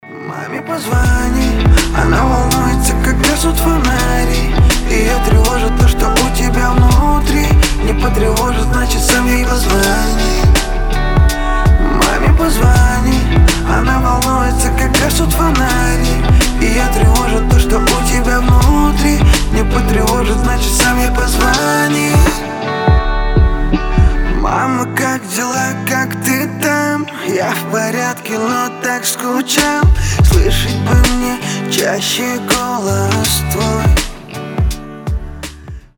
• Качество: 320, Stereo
гитара
мужской вокал
лирика
душевные